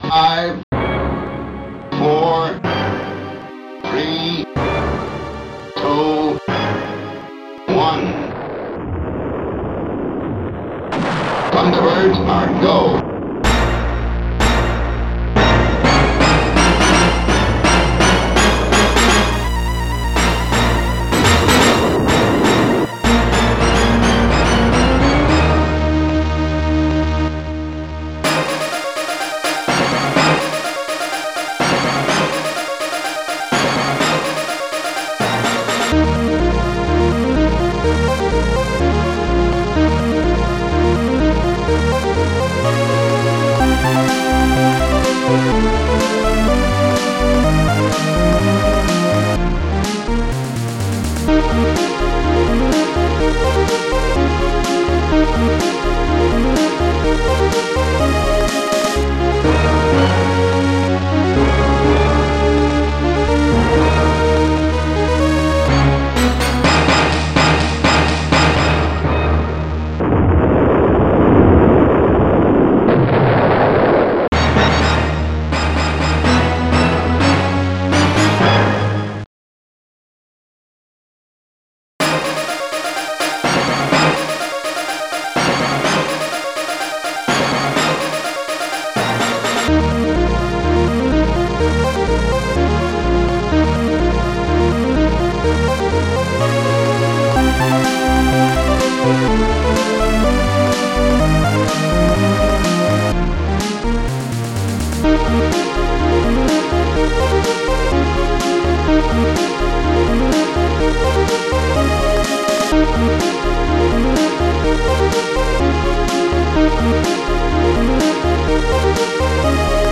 st-05:stringbass
st-02:mysnare1
st-06:orchblast